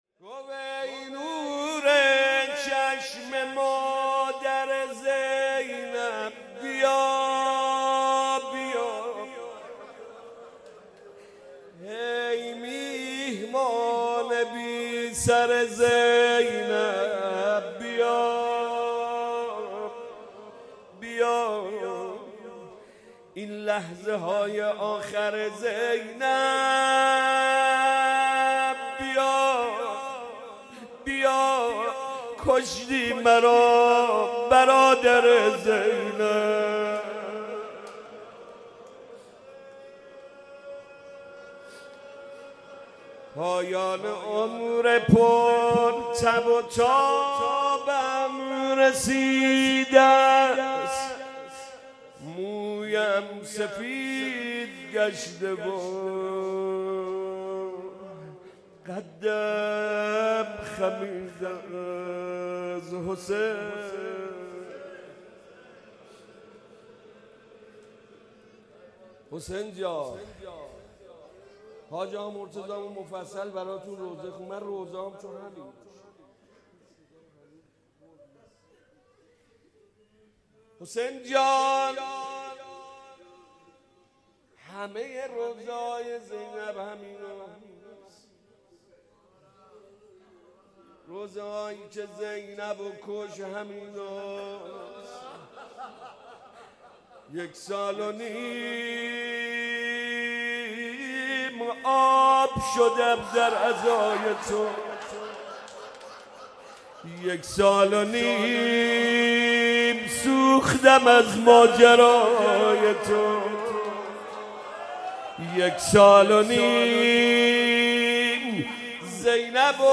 نوای سوزناک